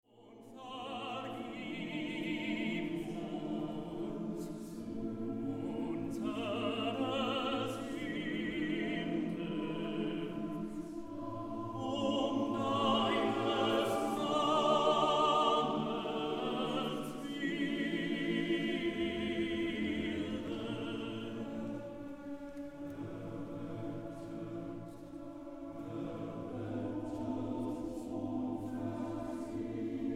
Festliches Konzert zu Ostern